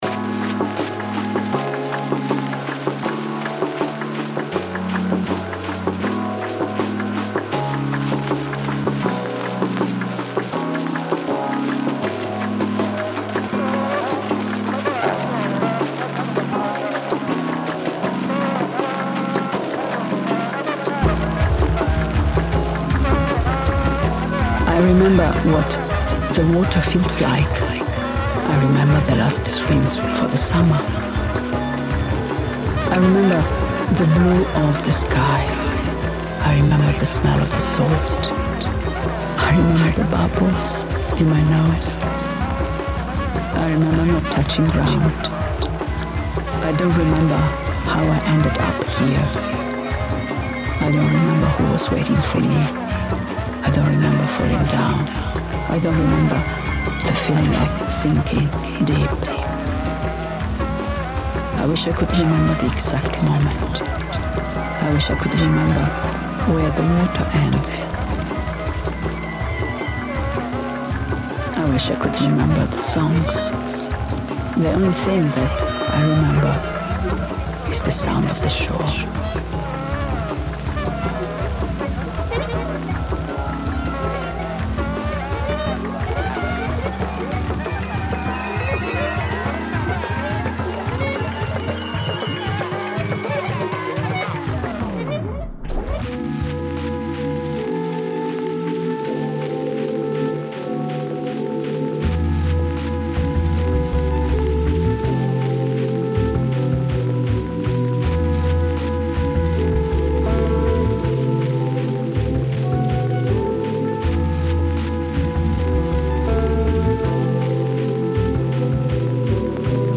jazz instrumentation
spoken word piece
Zande war song and chorus reimagined